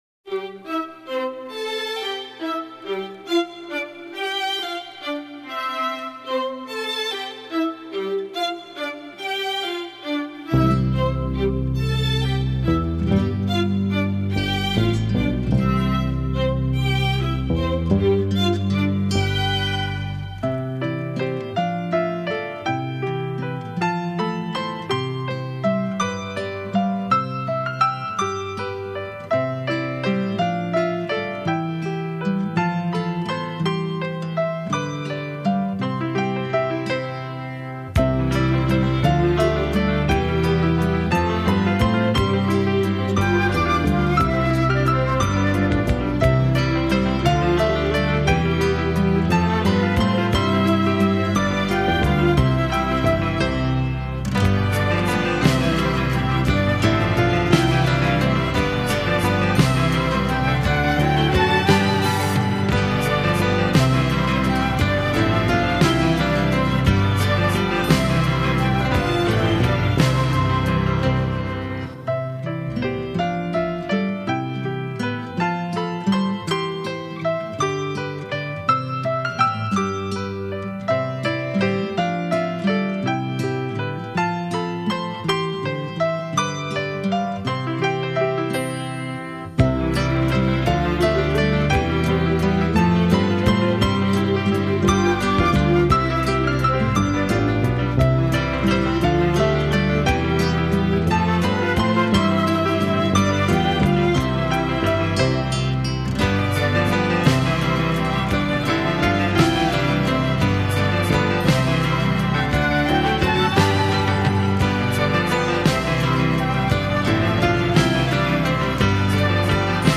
宫廷般华美的乐声中，吐露少女初尝爱情的浪漫情话